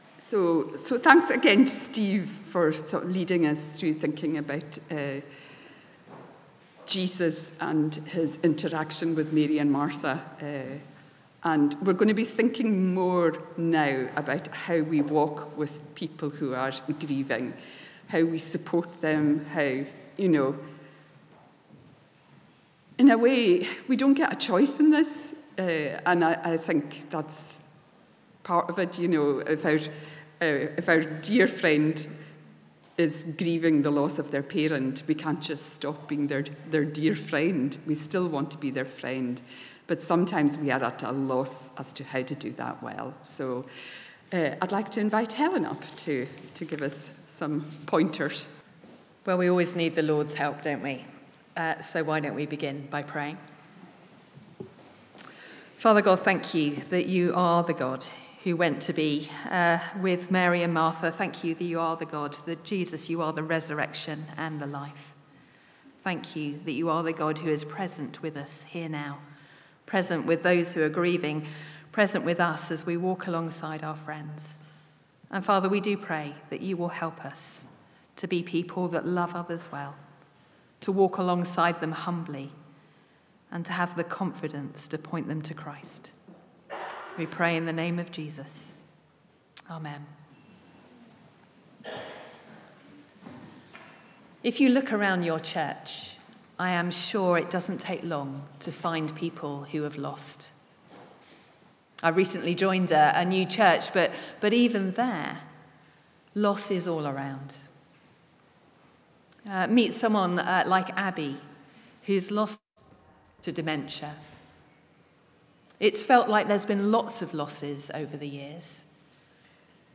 Session 2 fromGrieving with Hope (Edinburgh)Regional Conference, April 2024